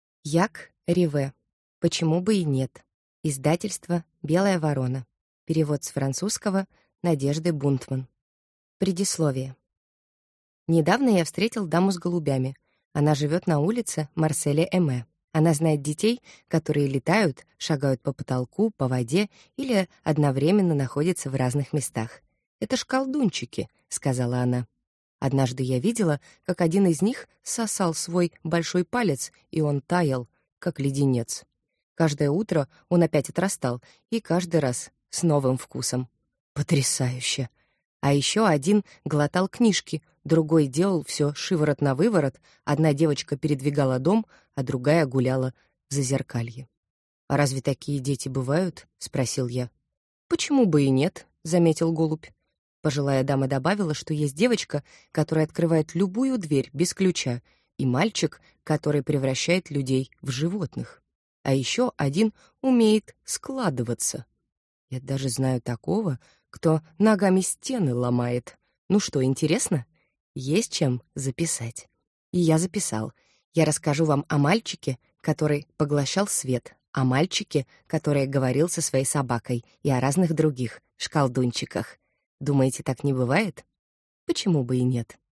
Аудиокнига Почему бы и нет? | Библиотека аудиокниг